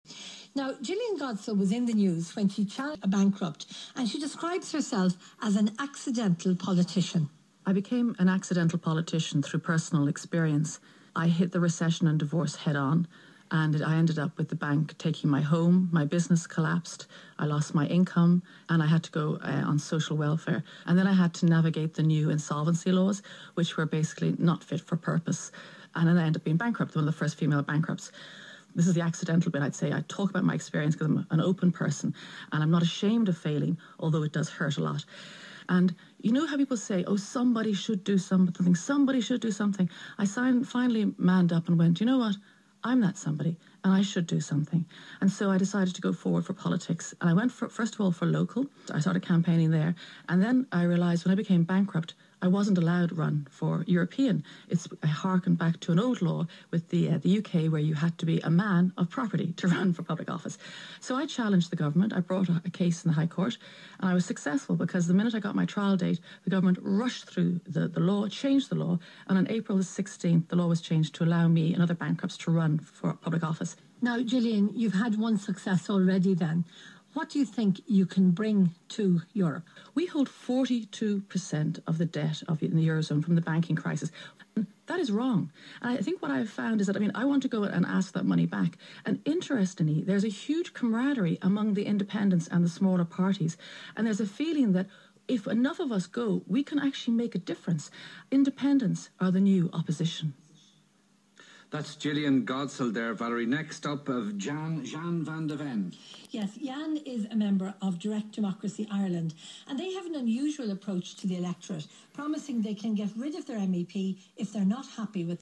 talking on RTE 1 Today with Sean O'Rourke